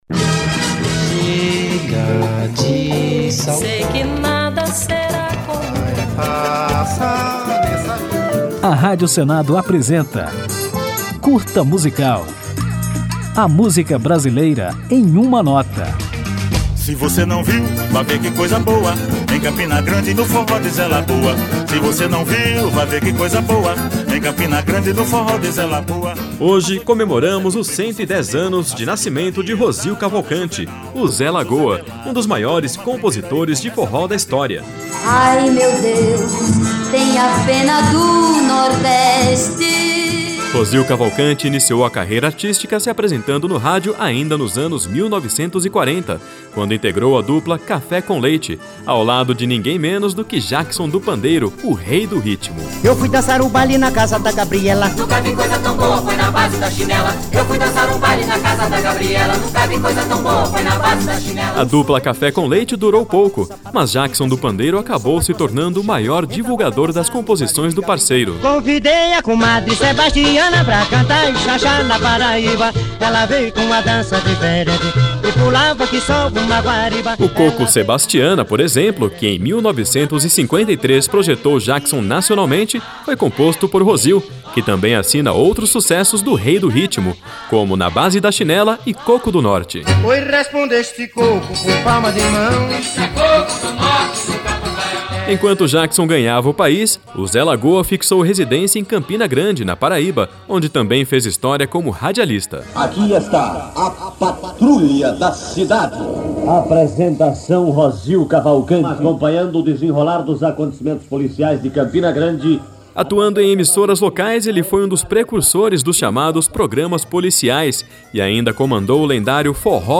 Ao final do programa ouviremos Jackson do Pandeiro com Sebastiana, o maior sucesso composto por Rosil Cavalcanti